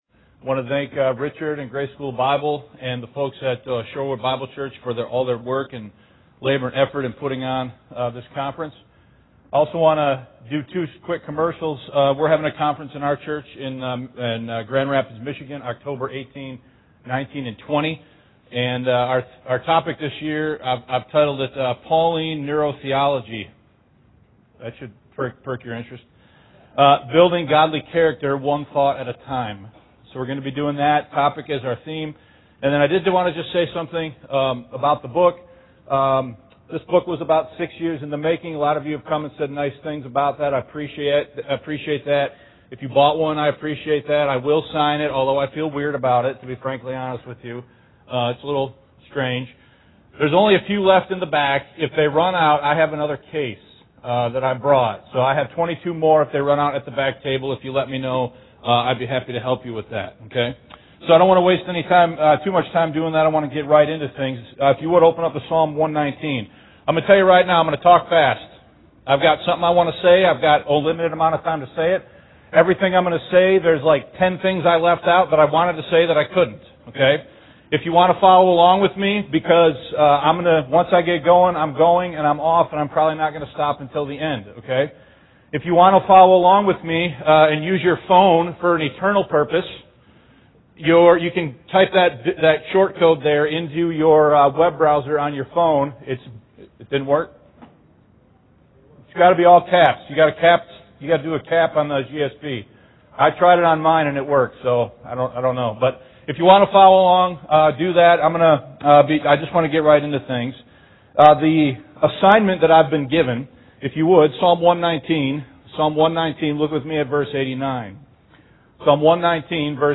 The Word for All Ages (2019 Grace School of the Bible Conference Message)